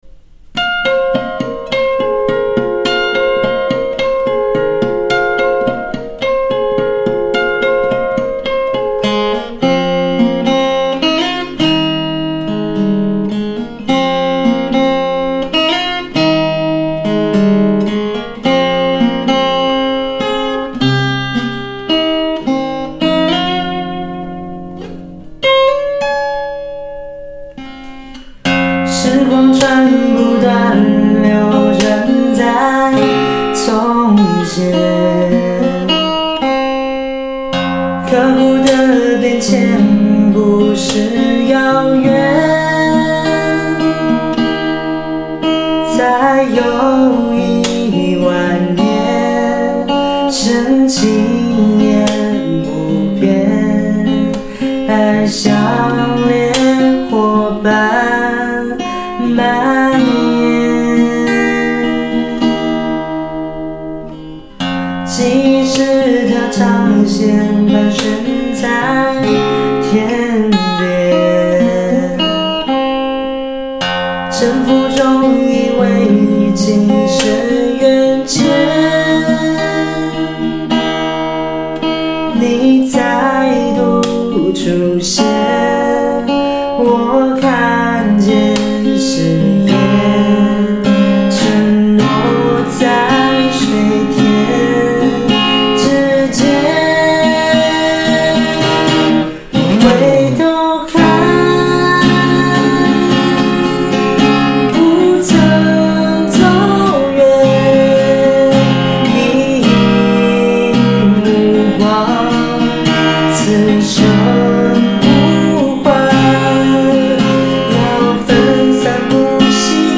1品G调指法